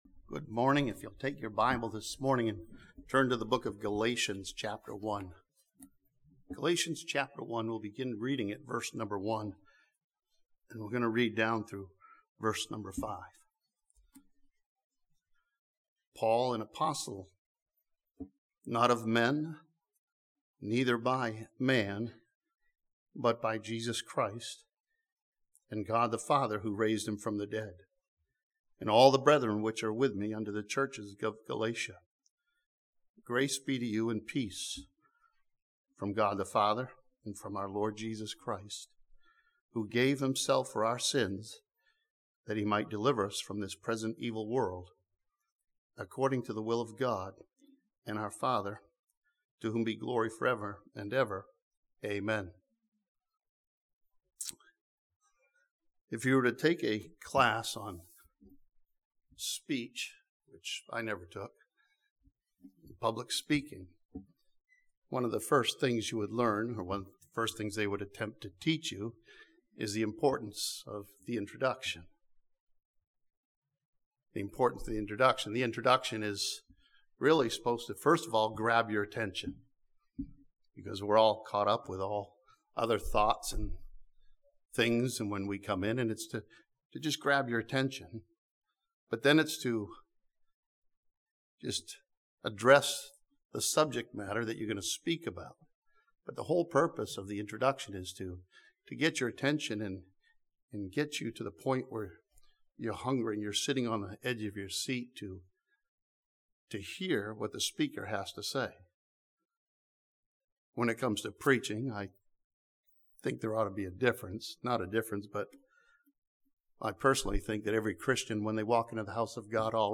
This sermon from Galatians chapter 1 challenges believers to see the cross of Christ and come to the cross.